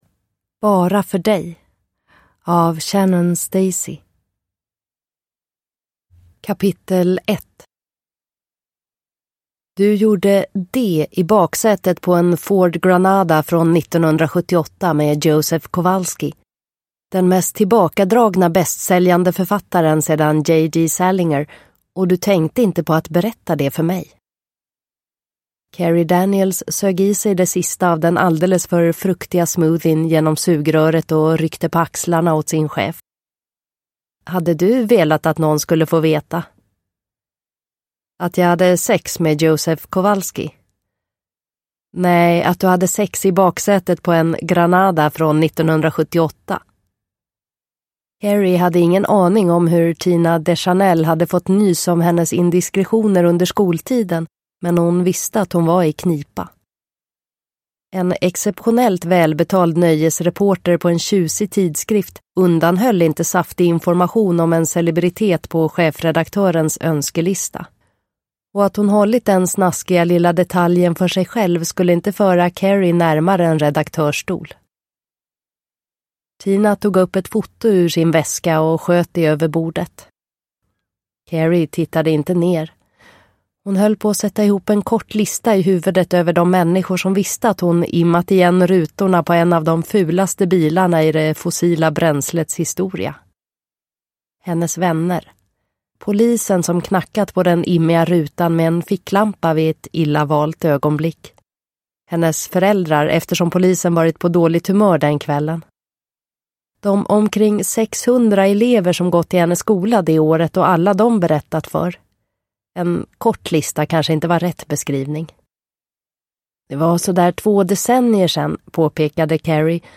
Bara för dig – Ljudbok – Laddas ner